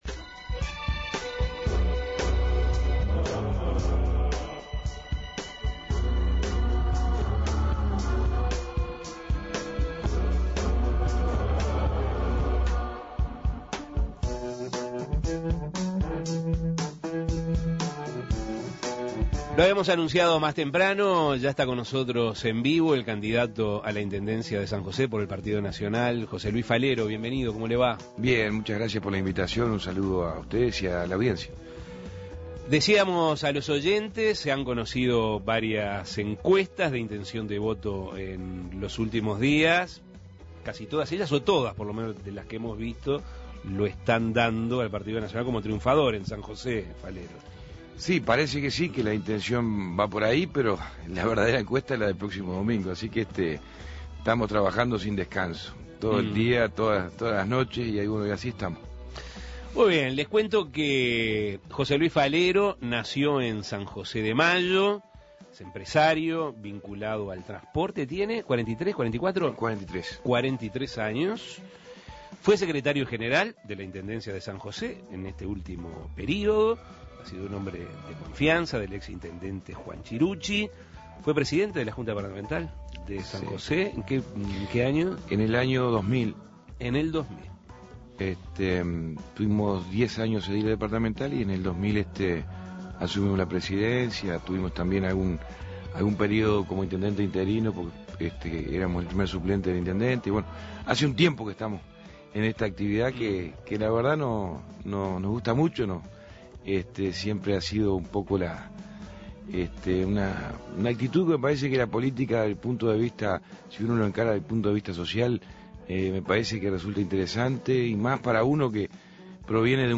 Las encuestas marcan que el departamento maragato optará por el continuismo blanco en la gestión municipal. El candidato que lidera las encuestas dentro del Partido Nacional es el herrerista José Luis Falero, quien estuvo en Asuntos Pendientes. Escuche la entrevista.